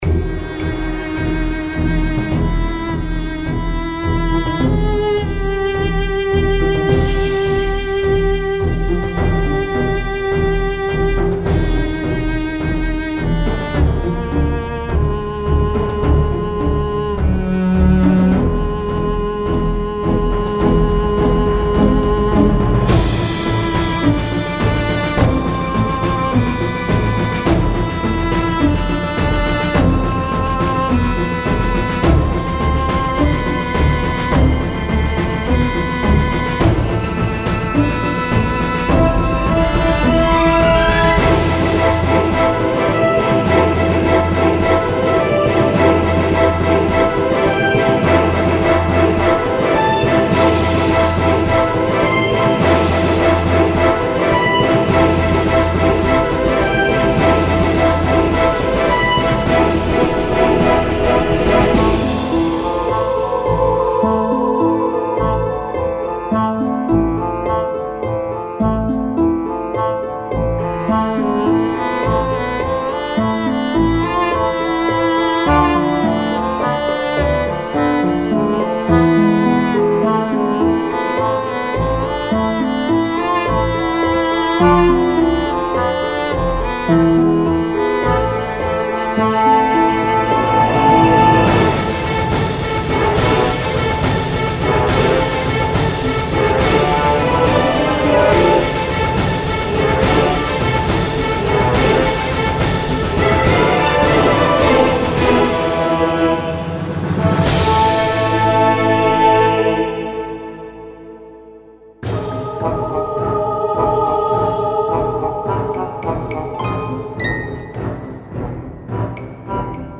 Very strange theme)